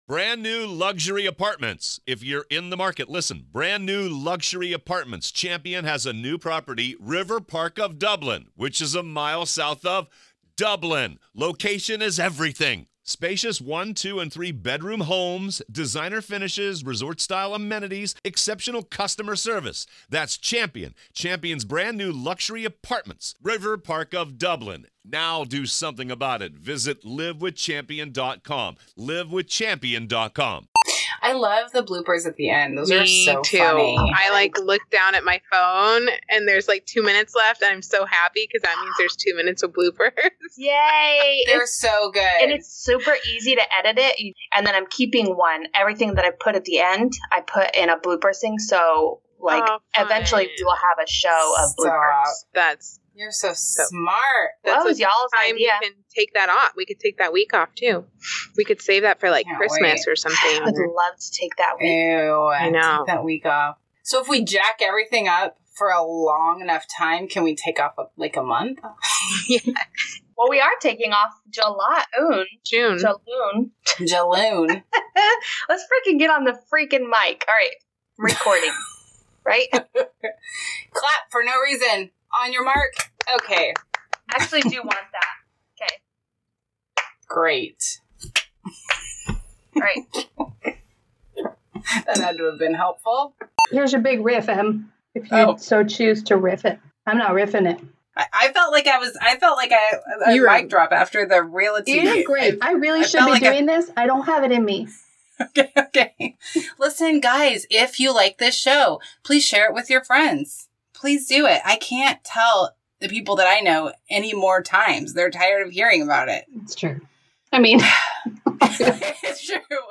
You guys, we jack up all of the time. We often get off topic, catch a case of the giggles and/or get interrupted by our kids when we are working.